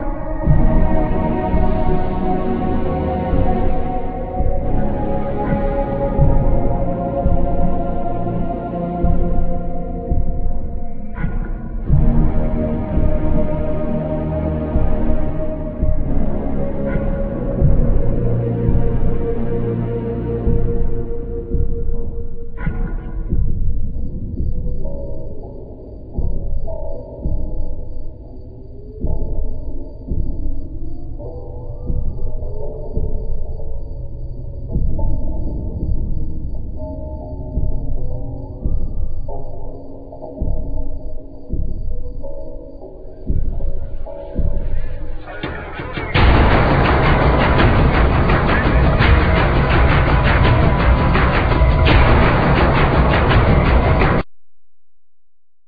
Electronics,Percussion,Voice